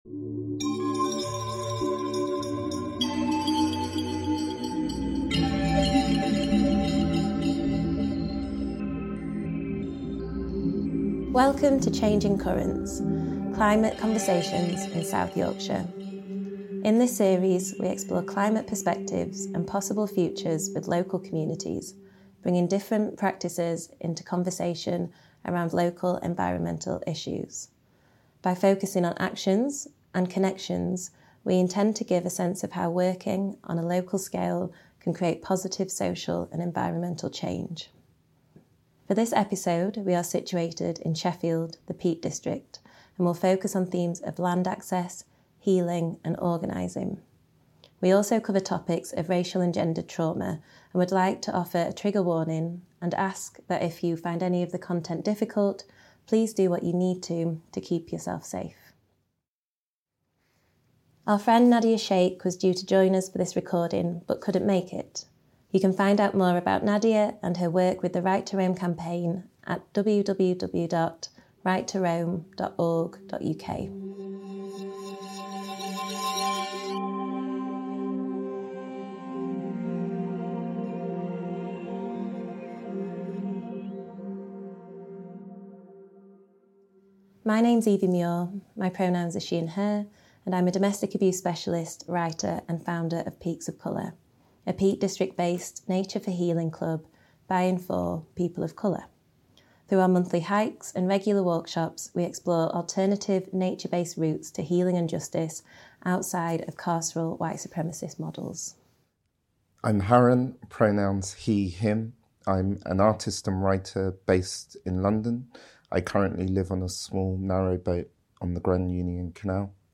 Changing Currents is a new podcast series exploring climate perspectives and possible futures featuring the voices of artists, growers, activists, local community groups, heritage workers and researchers across South Yorkshire.
This recording took place at the Longshaw Estate in the Peak District.